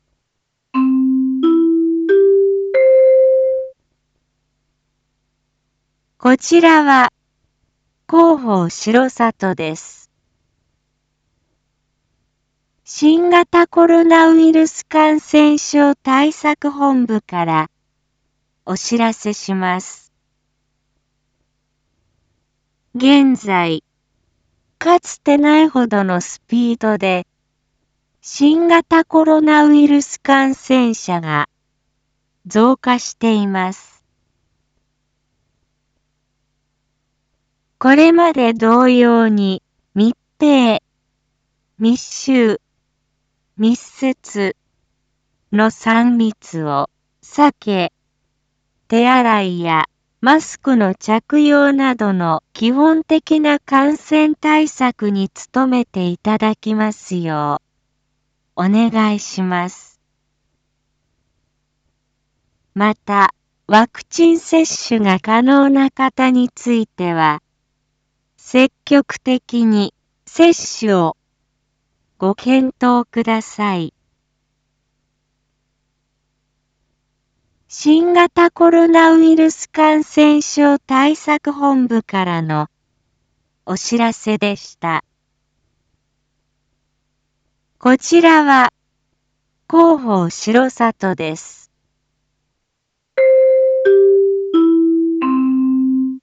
Back Home 一般放送情報 音声放送 再生 一般放送情報 登録日時：2022-07-24 19:01:37 タイトル：R4.7.24 19時放送分 インフォメーション：こちらは広報しろさとです。 新型コロナウイルス感染症対策本部からお知らせします。